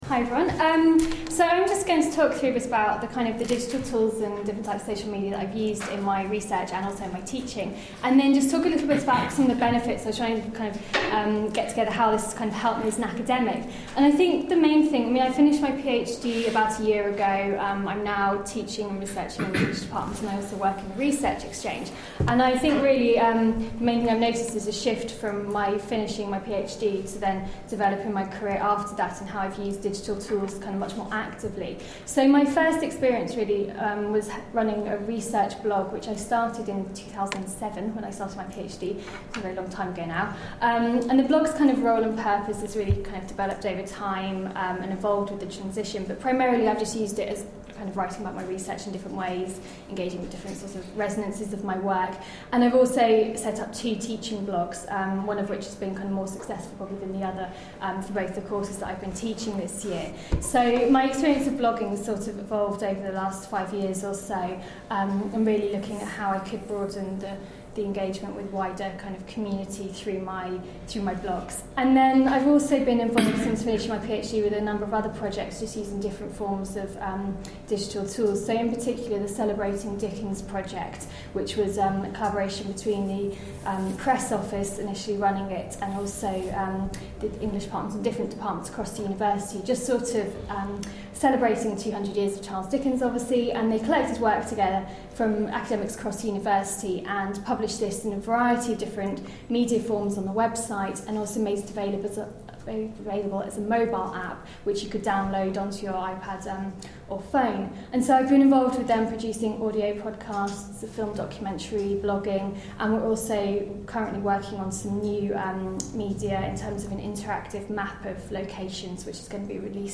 A podcast recorded at a Digital Change GPP event earlier this year.